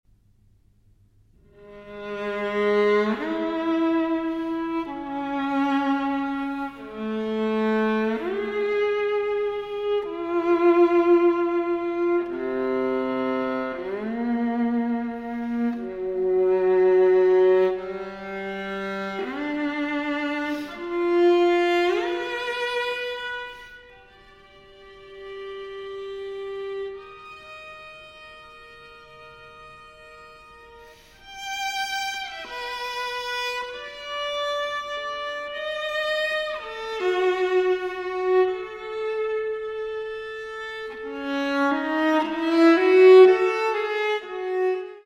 for Viola